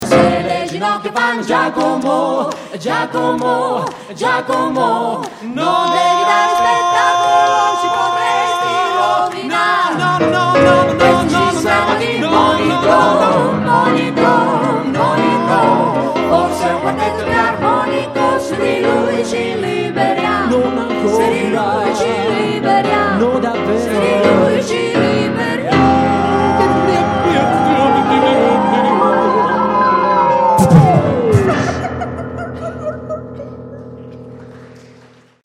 Le cinque voci
pianoforte
una riuscita fusione di musica e cabaret